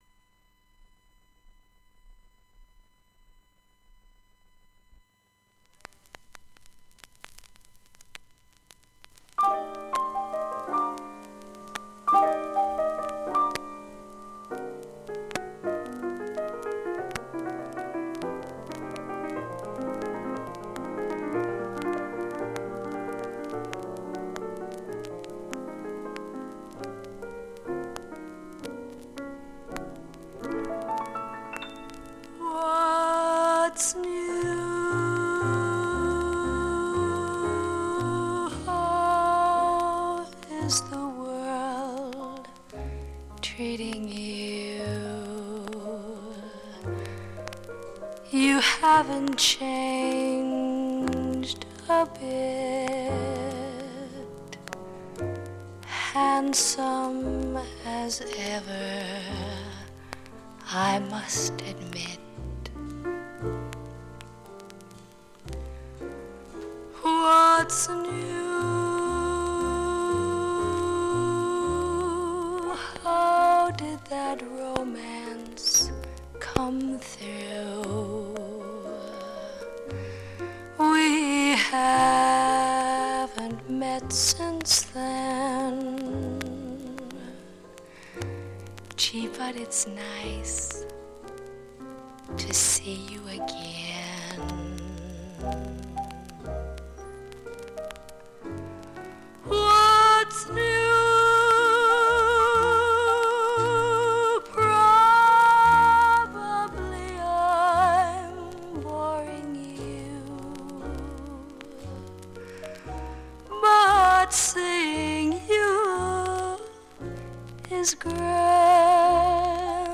変な音が５回とツクツクと６回出ます。
終盤は２箇所で、８回と４回のプツが出ます。
shure44gステレオ針での試聴です。